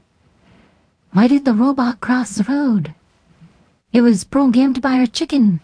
Looking into using a waifu voice changer to create a training dataset for a Piper TTS model, so far I found 2 voices that I liked.
"cross the road" is a bit more ara ara while "goat company" is more owo... which one do you think suits SPUD more?